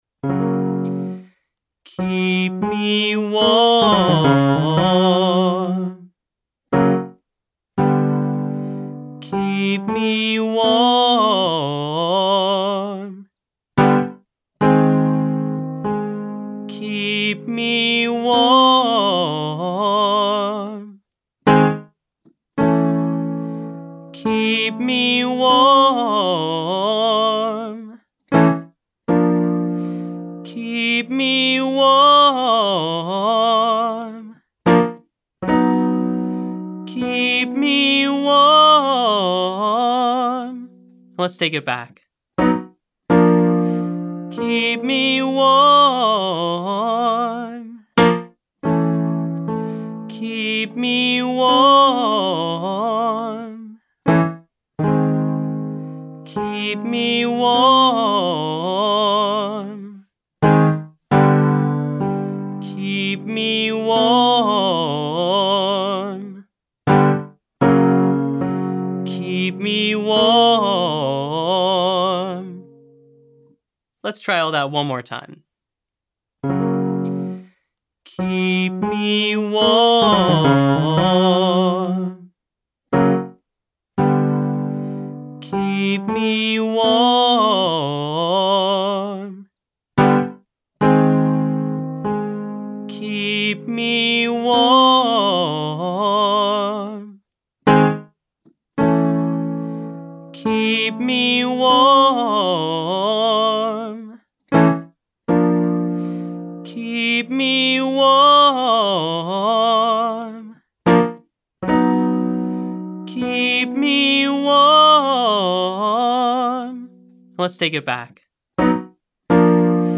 For the final portion, we’ll sing through an extended daily warm-up routine with no explanation. Each exercise will be repeated 3 times, then moved up the vocal range.
A) Staccato 1-5-1-5, 5-4-3-2-1
Vocal Agility Daily Warmup for Low Voice-4B